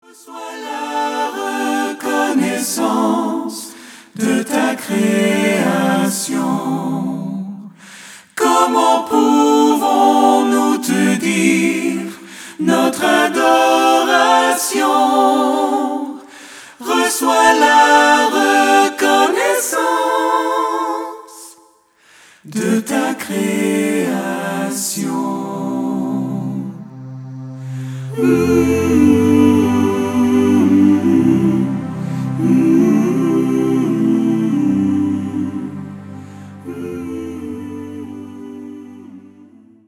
allant du pop au rock en passant par des ballades douces